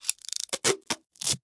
repair7.ogg